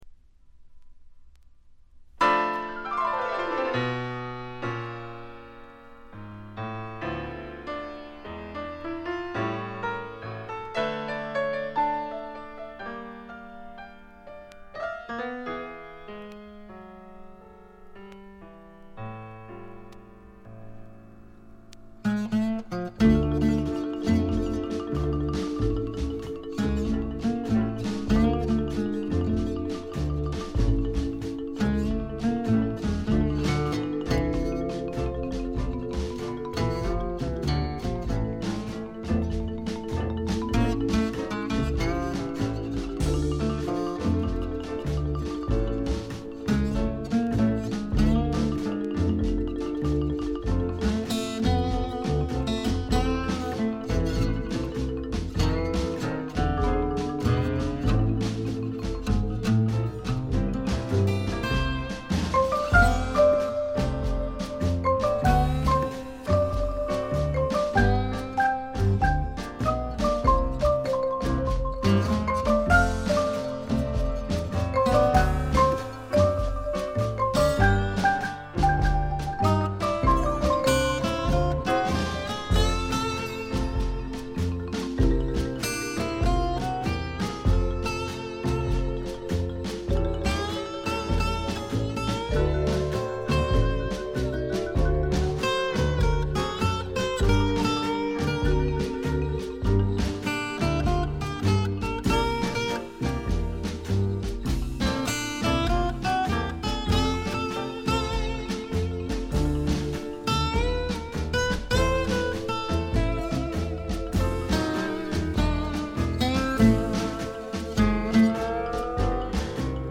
ディスク：部分試聴ですが、静音部での軽微なチリプチ少し。
試聴曲は現品からの取り込み音源です。